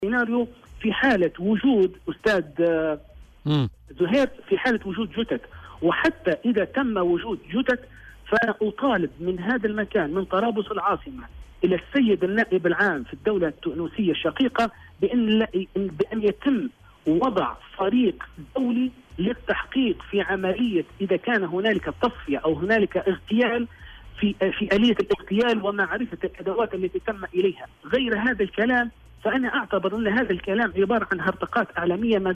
وجاءت دعوته في مداخلة هاتفية مع "بوليتيكا" على "الجوهرة أف أم" للتعليق على فيديو تم نشره على "فيسبوك" يتعلّق بتجسيد عملية تصفية الإعلاميين المفقودين.